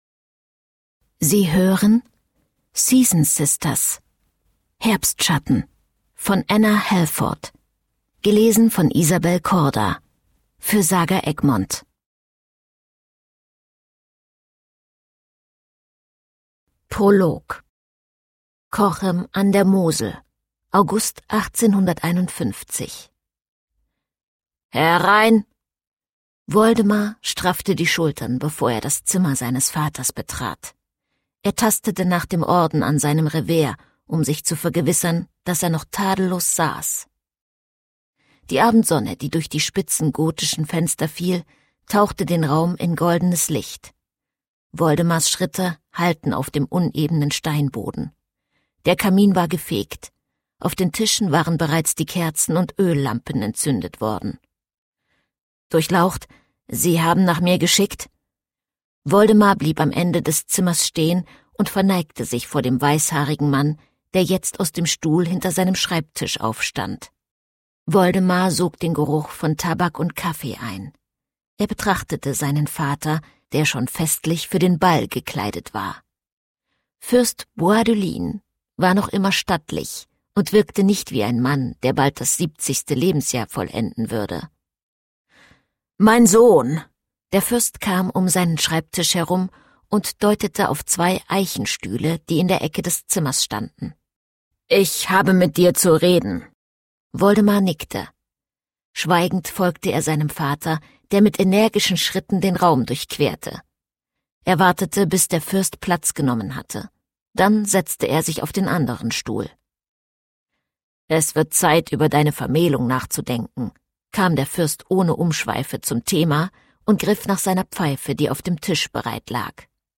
steinbach sprechende bücher | Hörbücher
Produktionsart: ungekürzt